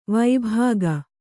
♪ vābhāga